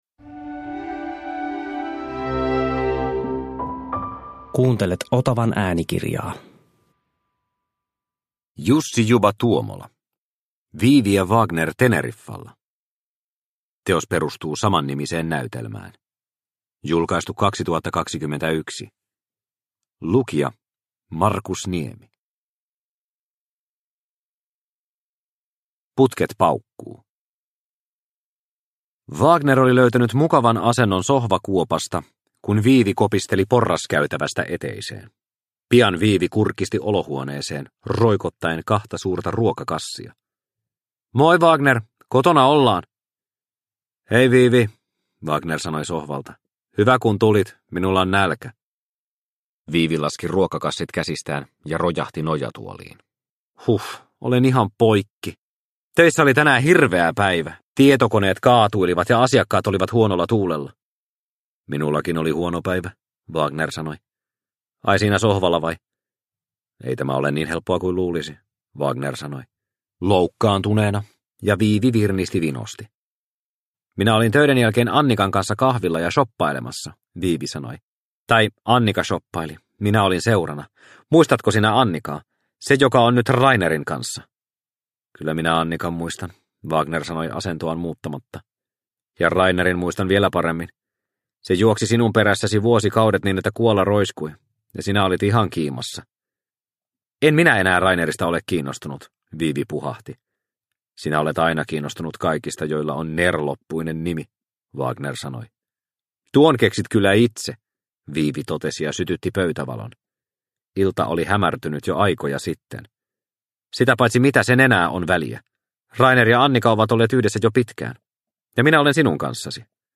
Viivi ja Wagner Teneriffalla – Ljudbok – Laddas ner